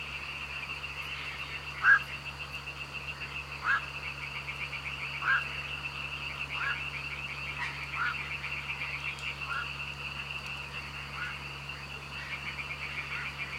Huairavo – Universidad Católica de Temuco
Huairavo-Nycticorax-nycticorax.mp3